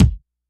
SBV_V12_Kick_005.wav